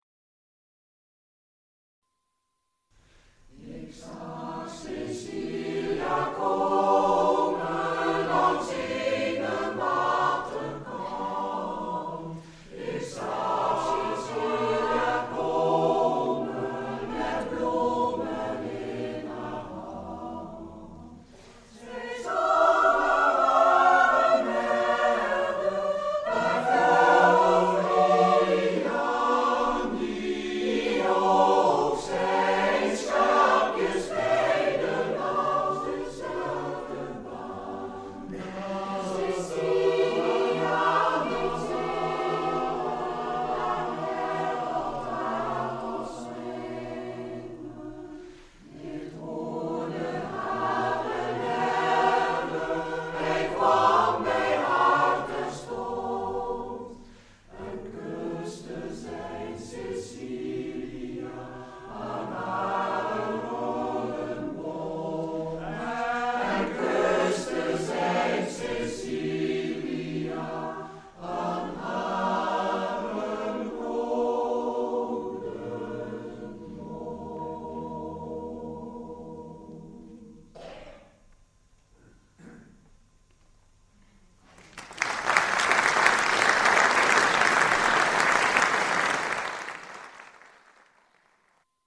Koorklanken
(Volkslied uit de 17e eeuw)